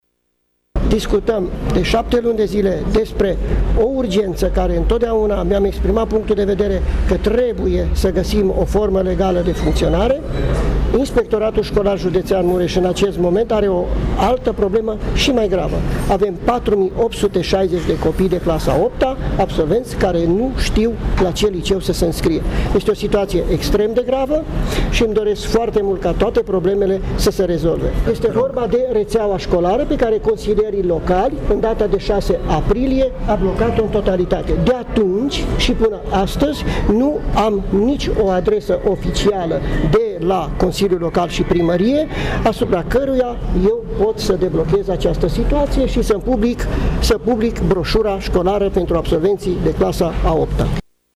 Inspectorul școlar general, Ioan Macarie, spune că situația este blocată de peste 7 luni, iar problema cea mai mare nu este Liceul Romano – Catolic, ci întreaga rețea școlară a municipiului Tg.Mureș: